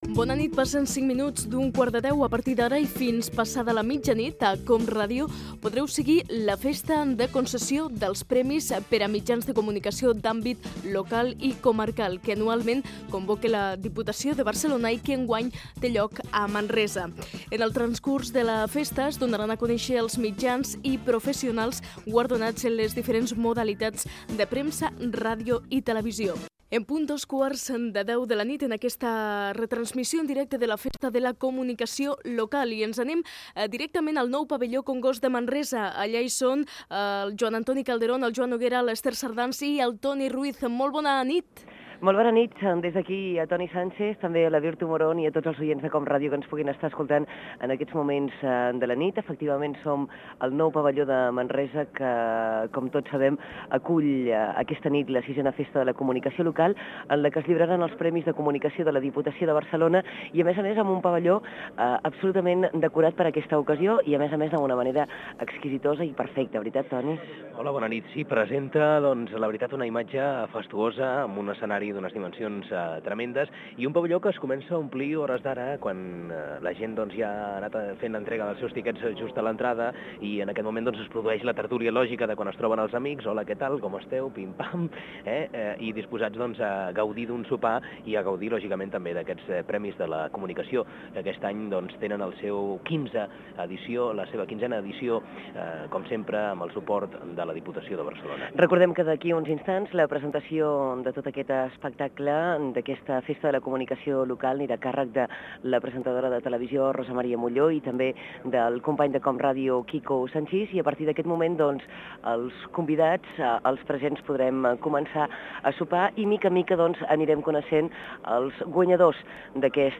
Ambient, categories i nombre de candidatures presentades l'any 1995 Gènere radiofònic Informatiu